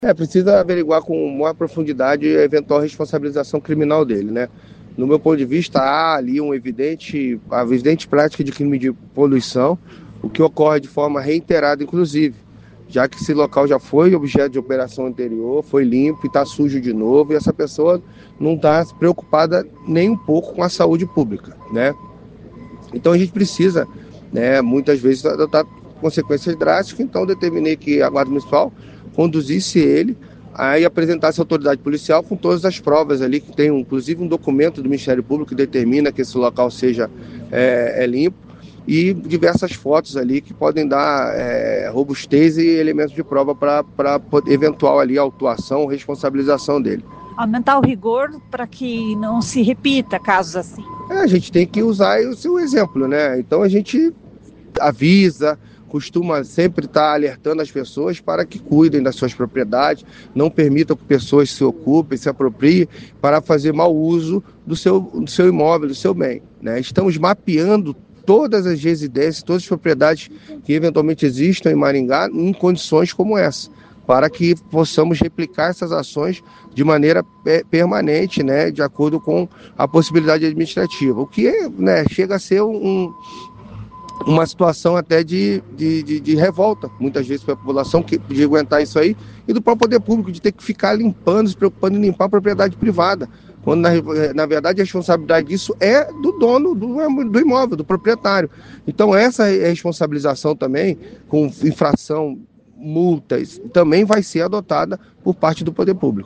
Ouça o que diz o secretário Luiz Alves